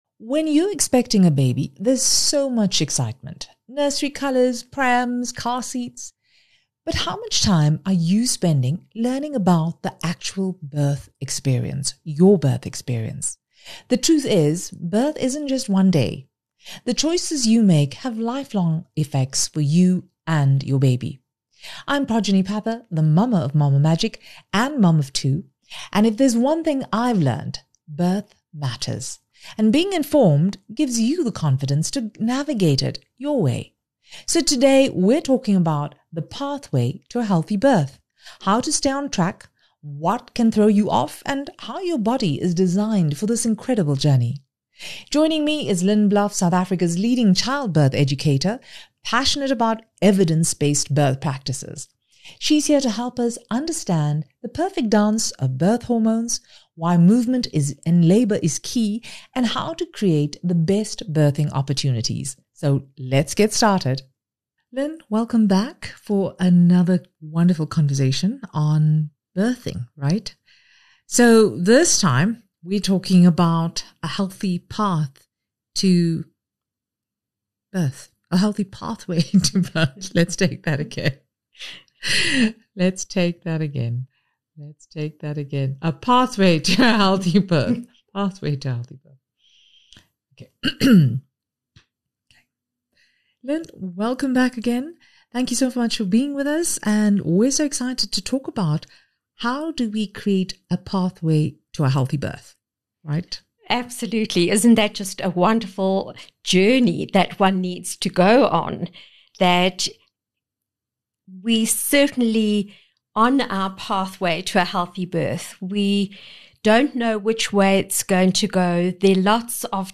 Discover how your body is designed for birth, the perfect dance of birth hormones, and why movement and informed choices make all the difference. Whether it’s your first birth or not, this conversation will help you feel empowered and prepared for the journey ahead.